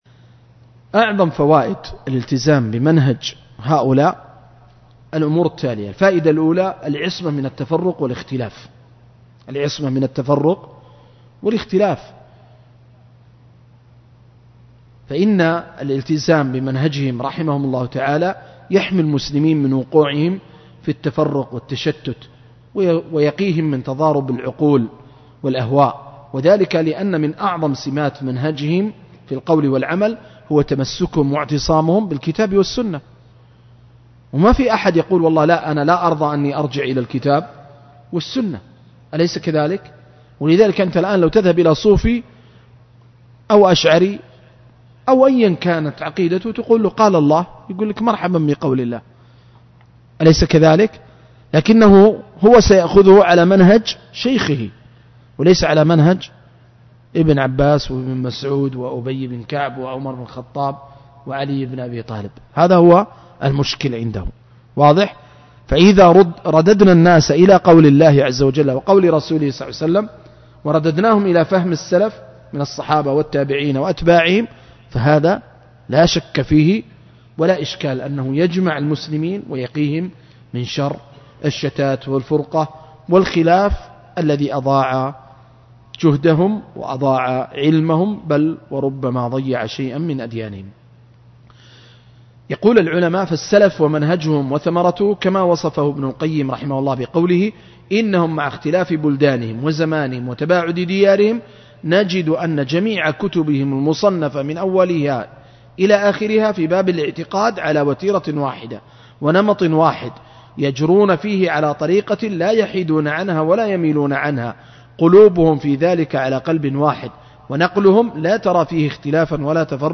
(دورة علمية في مسجد البلوي بالمدينة المنورة يوم الخميس 30/رجب/1435هـ)